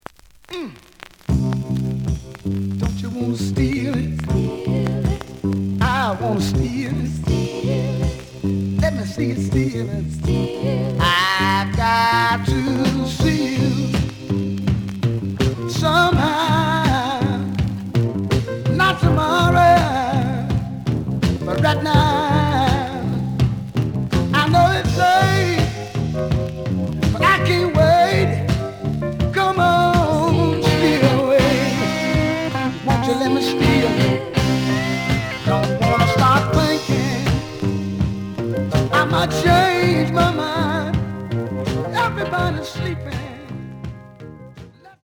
The audio sample is recorded from the actual item.
●Genre: Soul, 70's Soul
Slight edge warp.